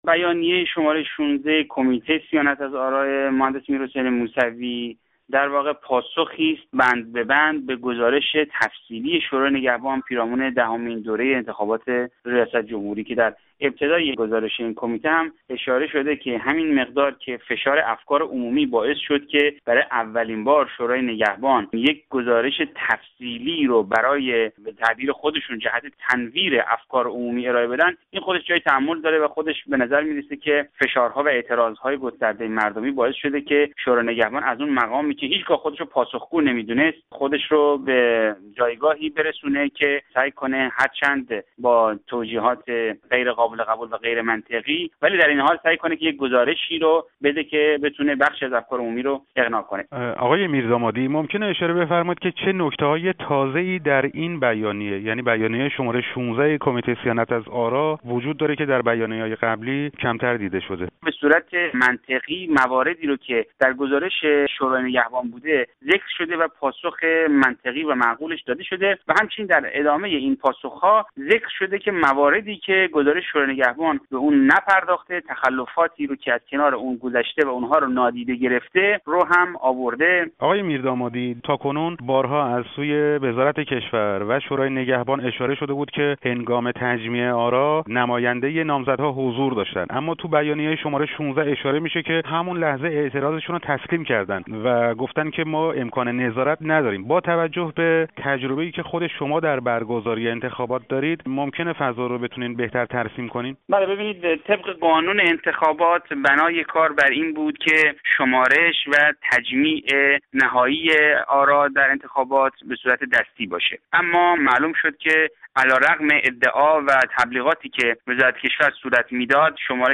بیانیه شورای نگهبان و «تردیدهای تازه انتخاباتی»-گفت‌وگو